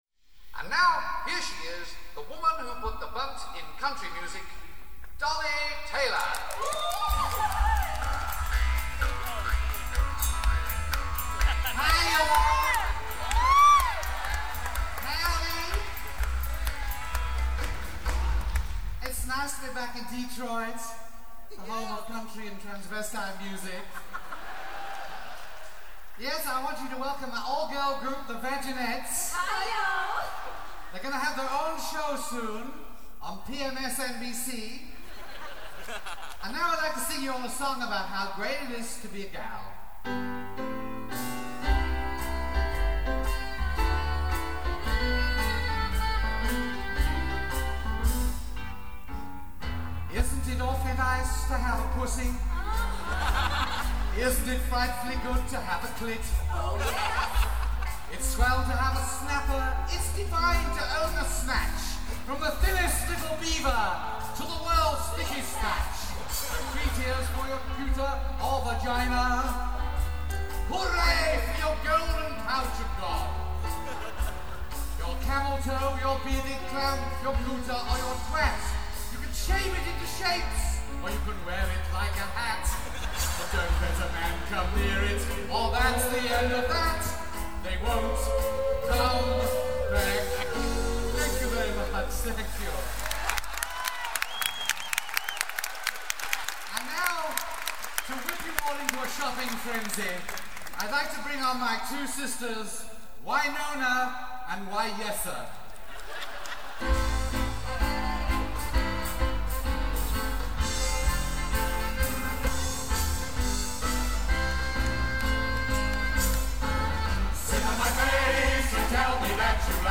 Warning, though: these contain strong, adult language!